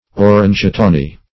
Orangetawny \Or"ange*taw`ny\, a. & n.